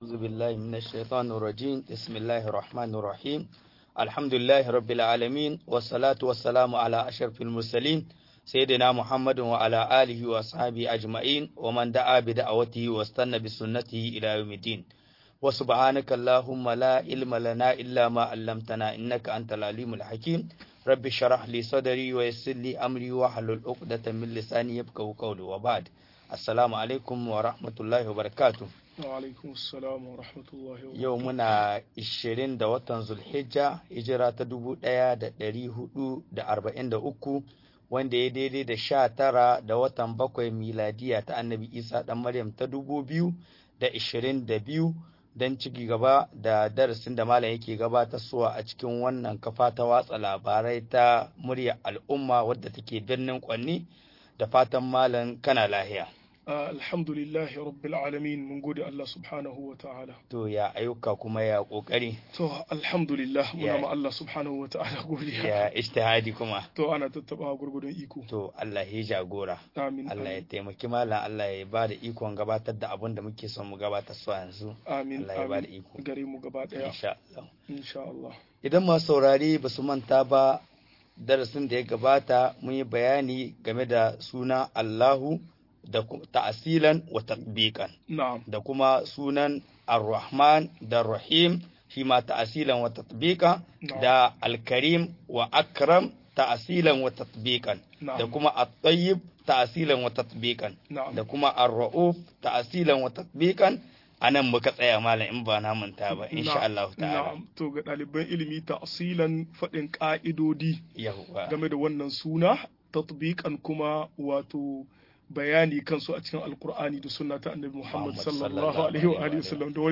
Sunayen Allah da siffofin sa-05 - MUHADARA